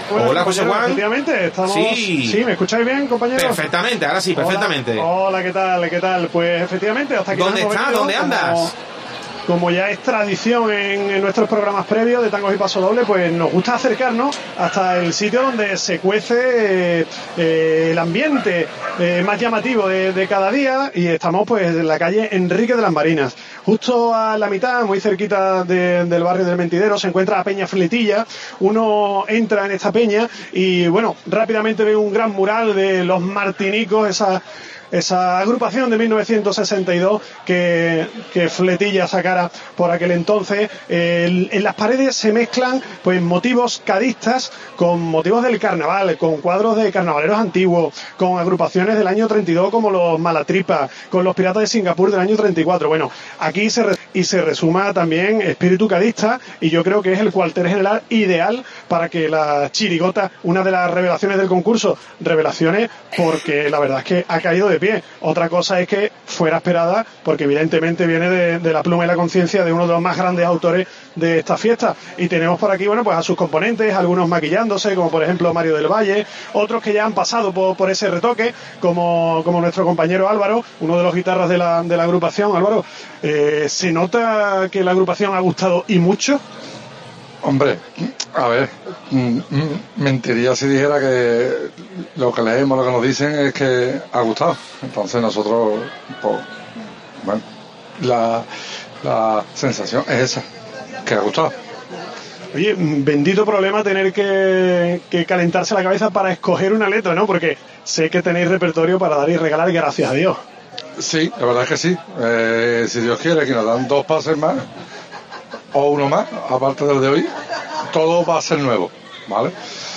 AUDIO: Nos colamos en el local donde se prepara la chirigota de Juan Carlos Aragón